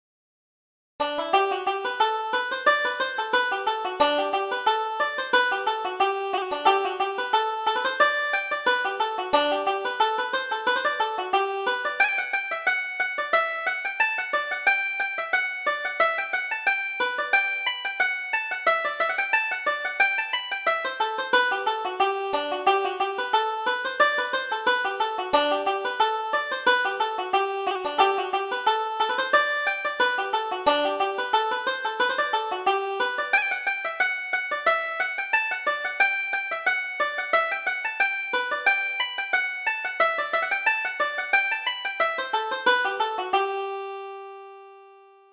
Reel: Leave My Way